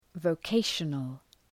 {vəʋ’keıʃənəl}